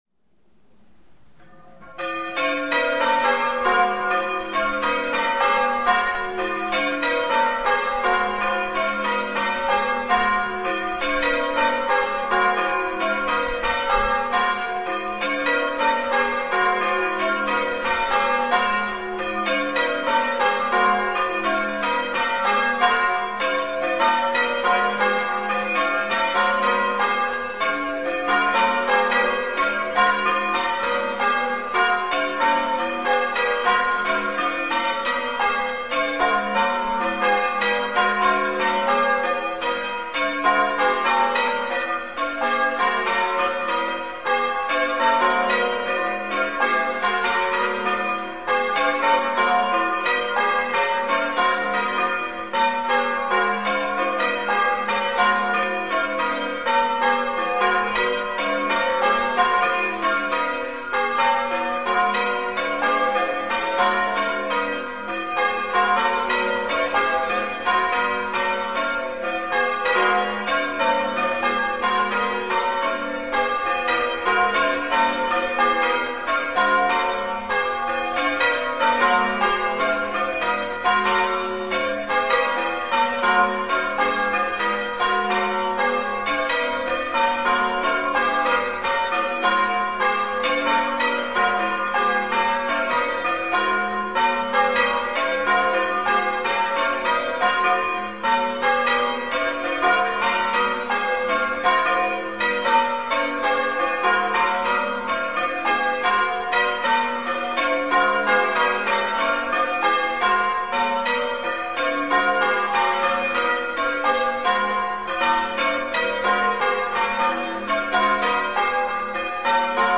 6 bells, 16-0-0 in F